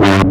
Bass x-tra 1.106.wav